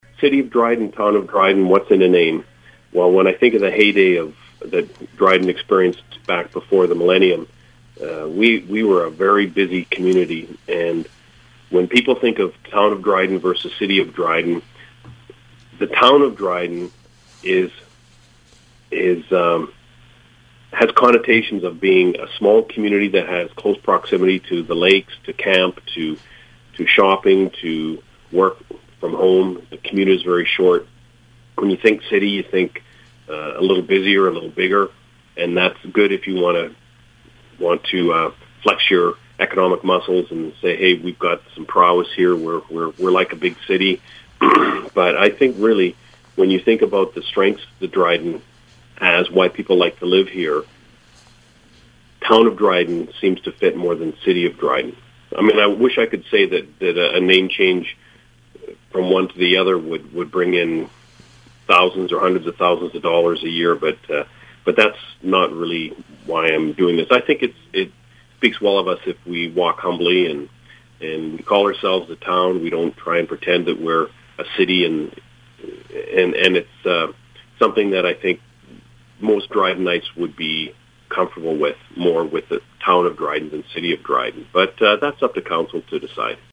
mayorweb.mp3